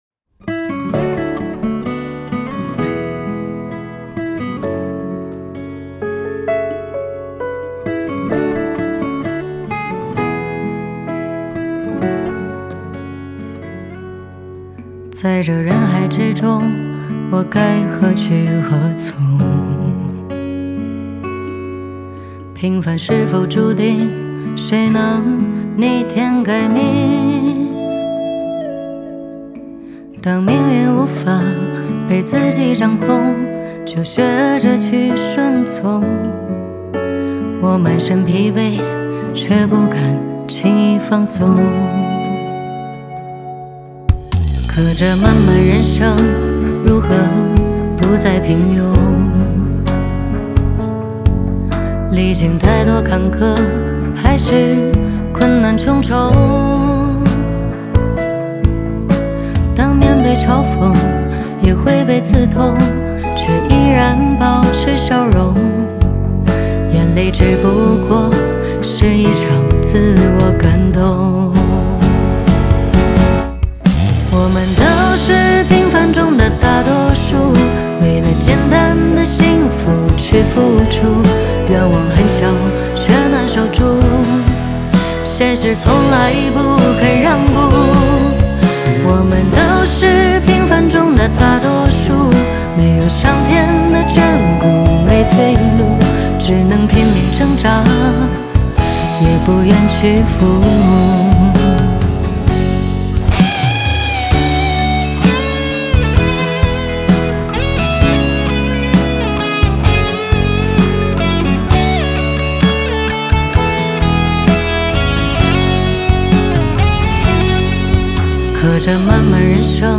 Ps：在线试听为压缩音质节选，体验无损音质请下载完整版 在这人海之中，我该何去何从， 平凡是否注定，谁能逆天改命。